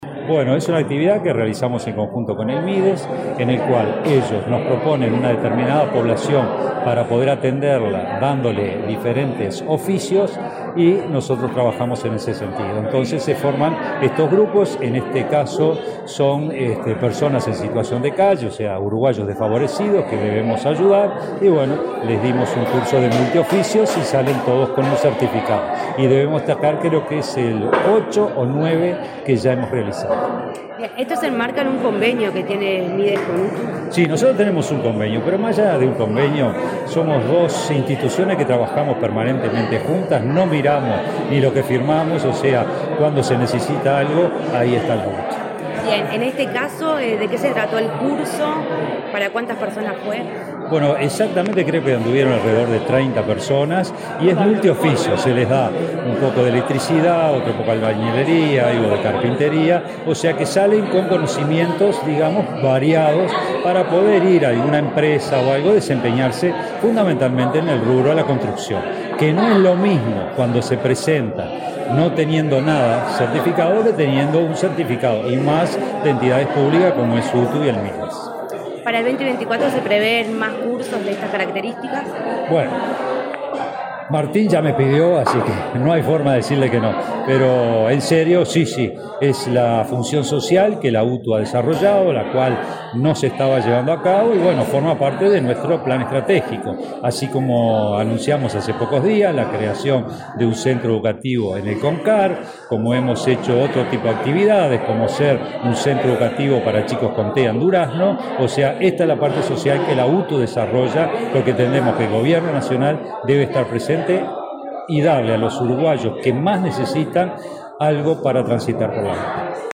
Declaraciones del director general de UTU, Juan Pereyra
Declaraciones del director general de UTU, Juan Pereyra 14/12/2023 Compartir Facebook X Copiar enlace WhatsApp LinkedIn Este jueves 14 en Montevideo, el director general de UTU, Juan Pereyra, dialogó con Comunicación Presidencial, luego de participar de la entrega de certificados de la octava edición del curso multioficios.